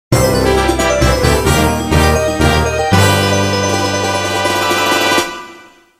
theme
Fair use music sample